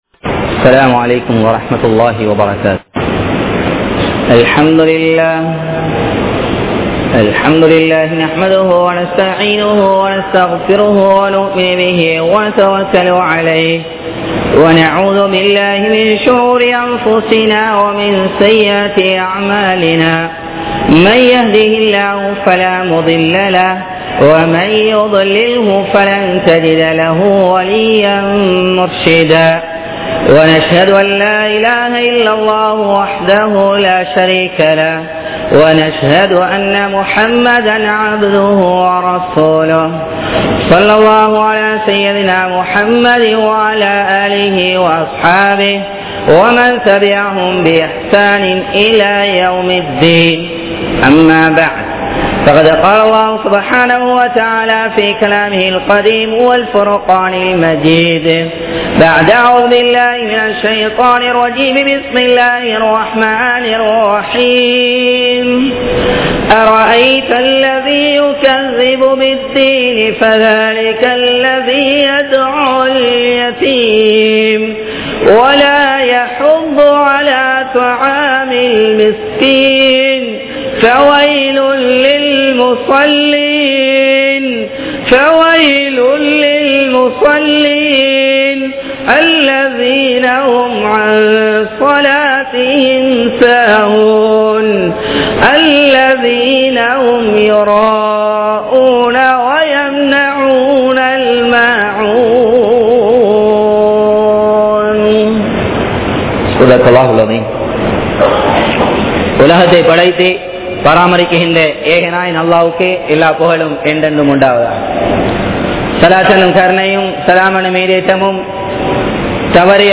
Neengal Tholum Murai Seithaanaa? (நீங்கள் தொழும் முறை சரிதானா??) | Audio Bayans | All Ceylon Muslim Youth Community | Addalaichenai
Dehiwela, Muhideen (Markaz) Jumua Masjith